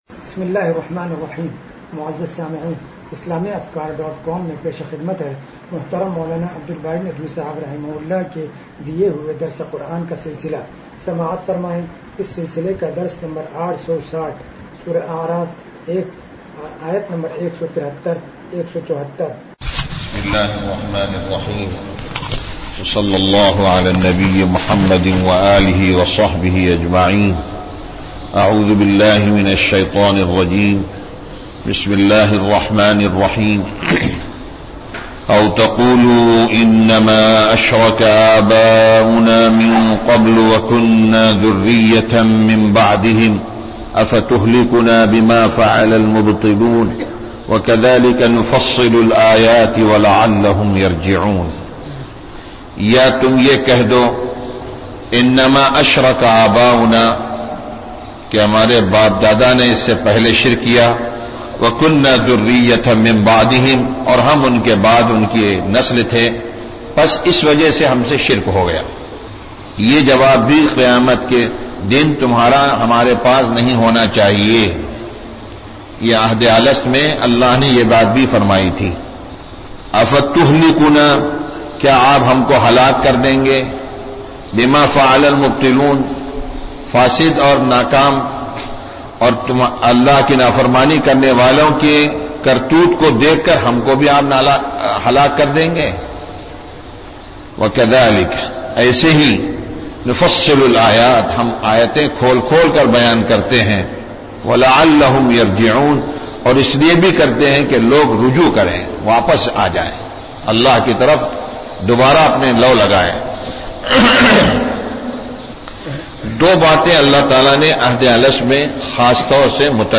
درس قرآن نمبر 0860